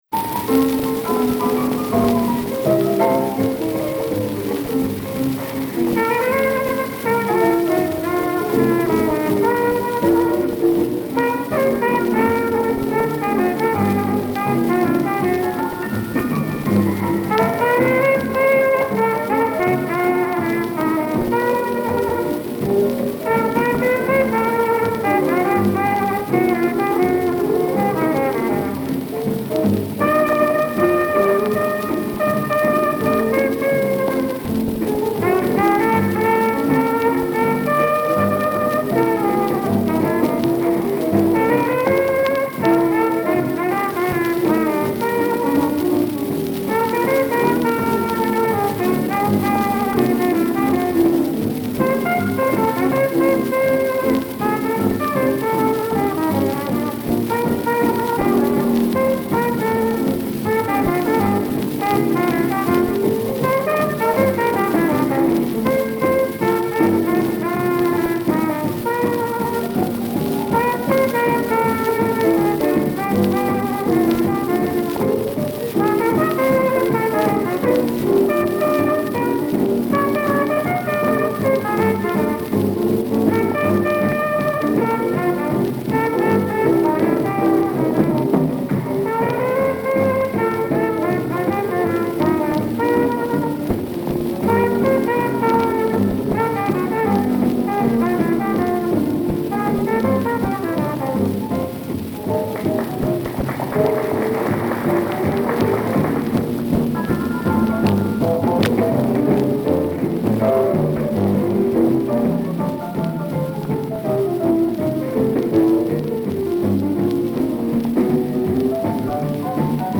(-3% speed correction)